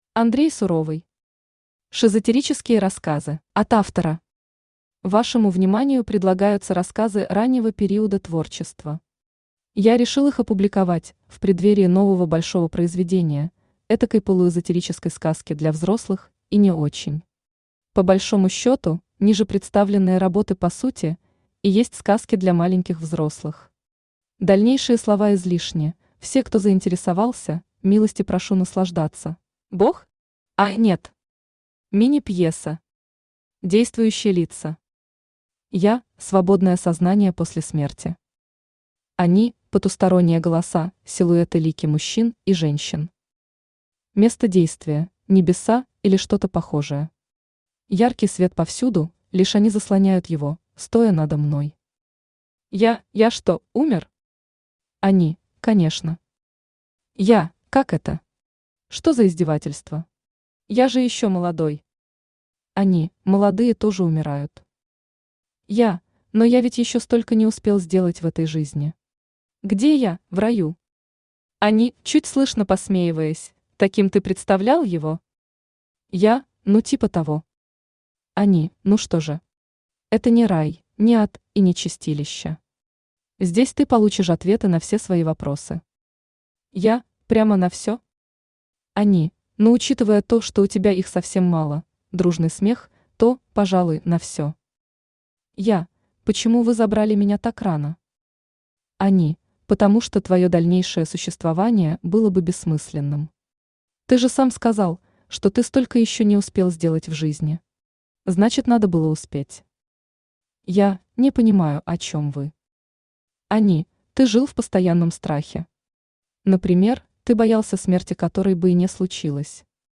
Аудиокнига Шизотерические Рассказы | Библиотека аудиокниг
Aудиокнига Шизотерические Рассказы Автор Андрей Суровый Читает аудиокнигу Авточтец ЛитРес.